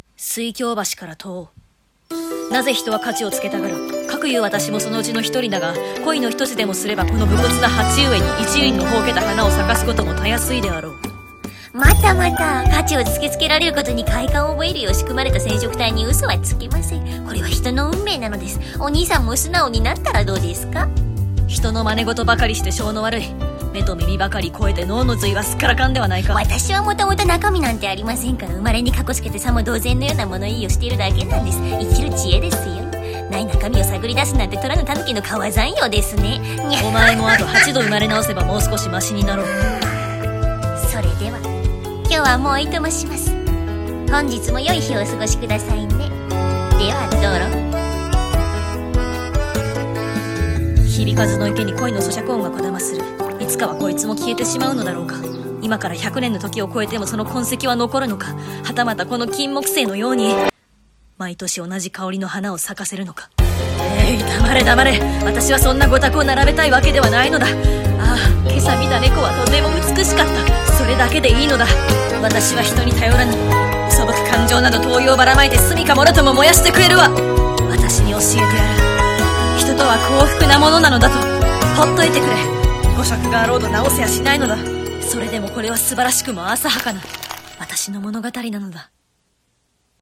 【1人2役】CM風声劇「とある文学少年の一説」